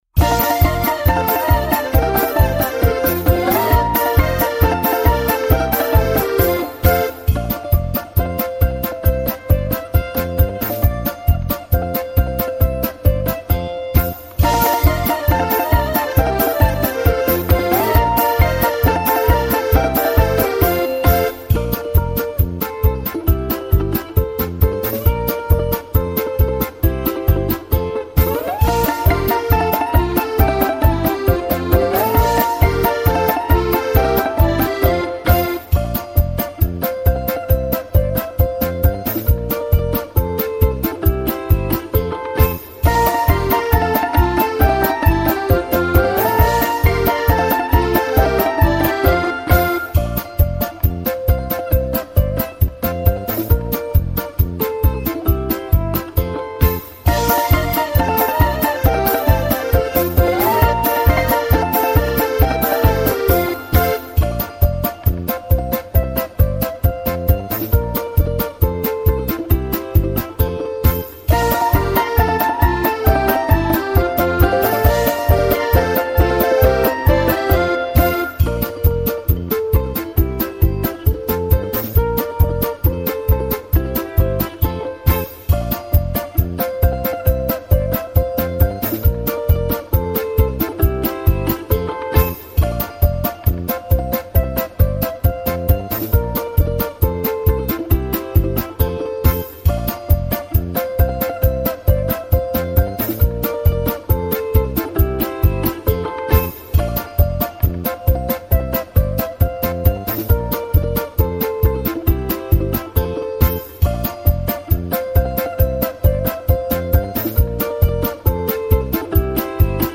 Детская музыка под частушки